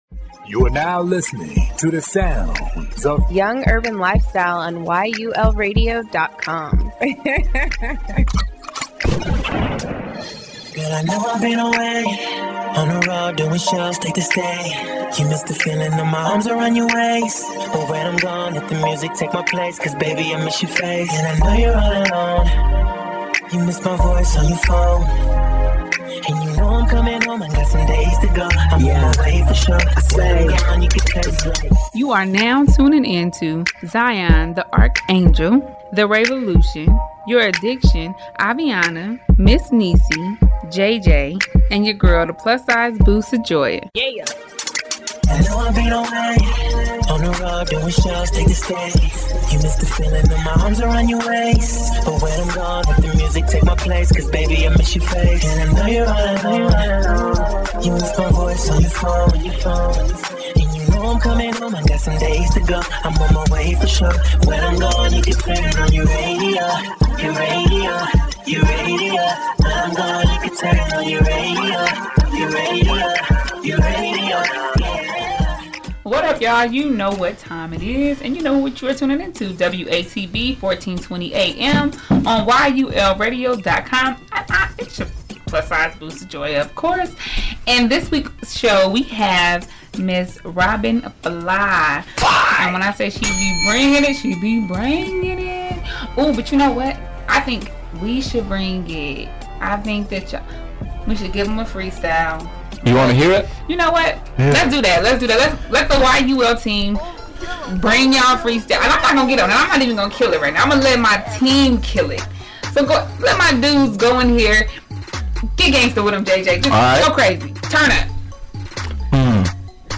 Interviews
Check out her interview with the YUL team.